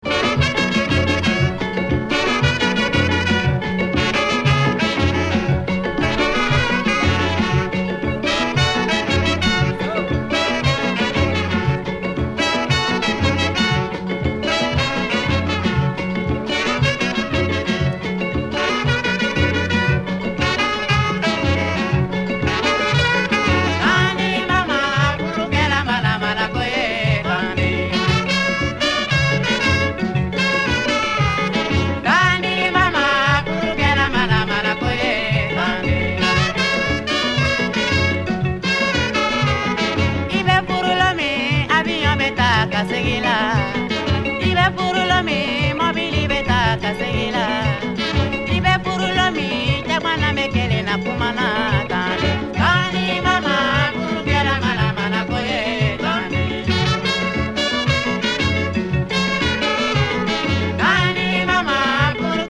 Completely restored artwork and remastered sounds.